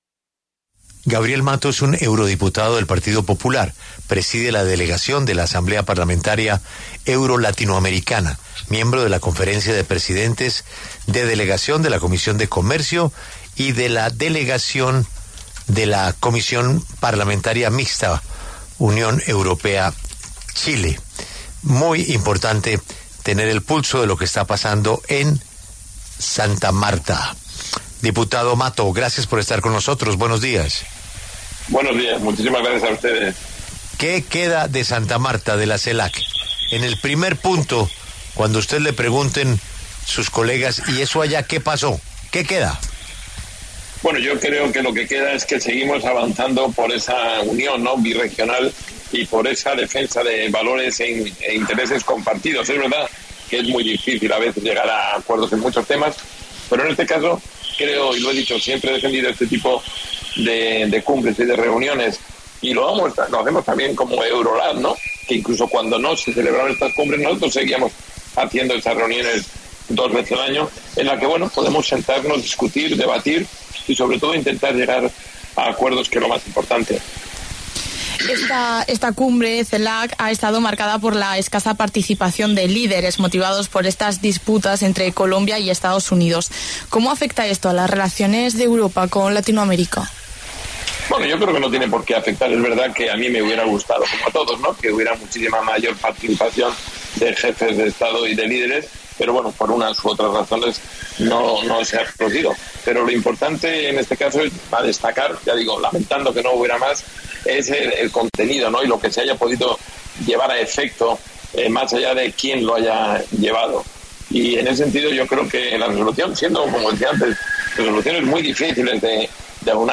Gabriel Mato, eurodiputado del Partido Popular y presidente de la Delegación en la Asamblea Parlamentaria Euro-Latinoamericana, pasó por los micrófonos de La W para hablar sobre la Cumbre CELAC-UE que se celebra en Santa Marta.